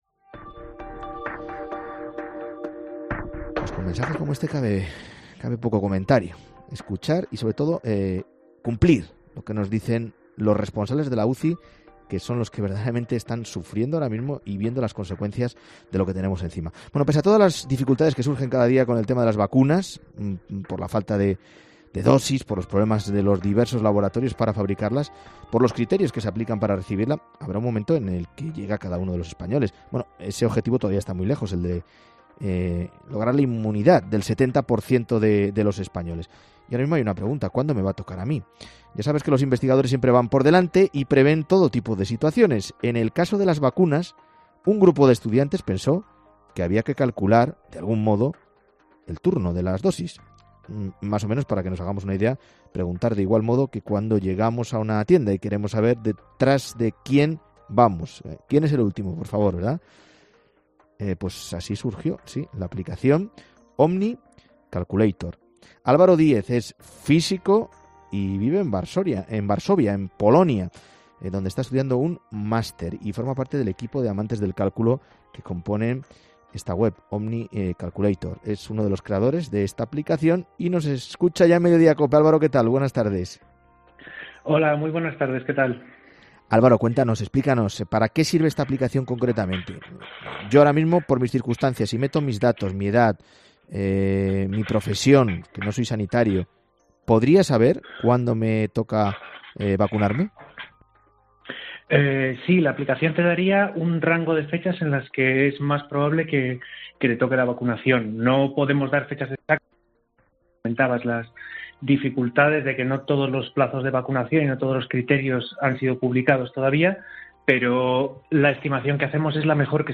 Es una web que puede visitar cualquier persona y en "Mediodía COPE" nos ha contado cómo funciona: “La app te daría un rango de fechas en las que es más probable que te toque la vacunación.